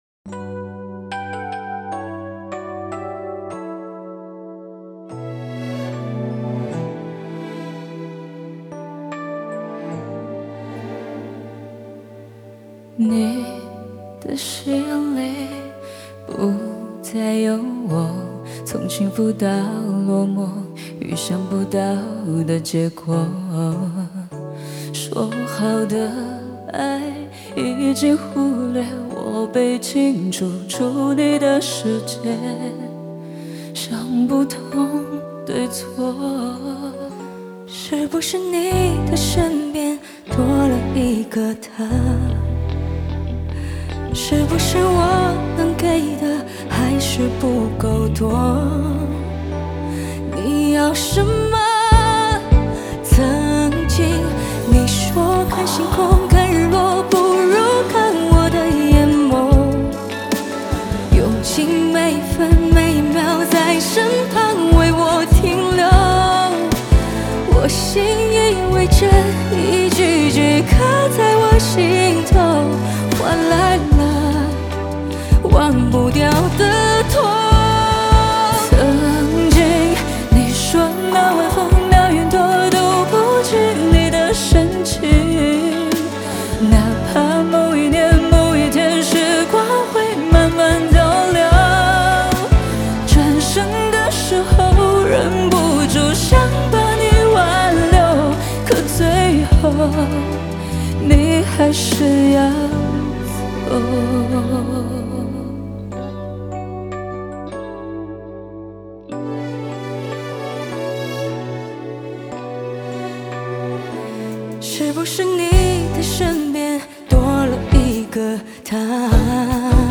Ps：在线试听为压缩音质节选，体验无损音质请下载完整版
鼓
打击乐
贝斯
吉他
钢琴
键盘